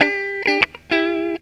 GTR 55 EM.wav